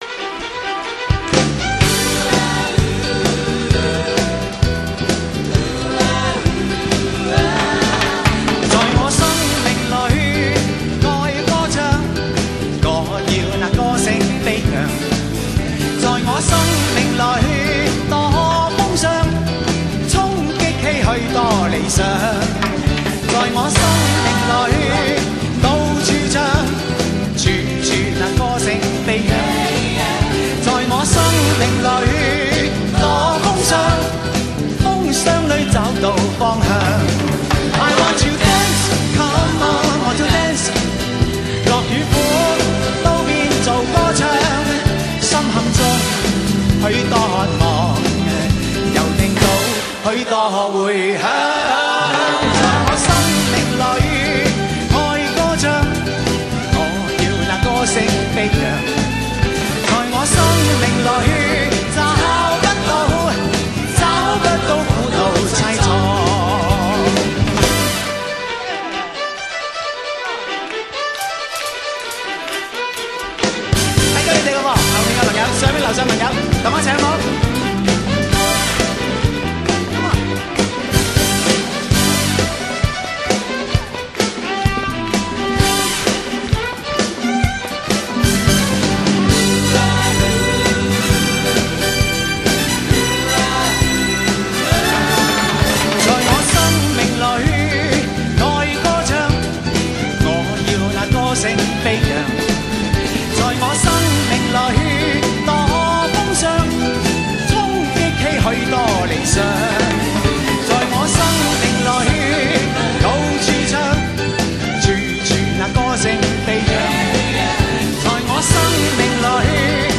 迷人的嗓音 听百遍都不腻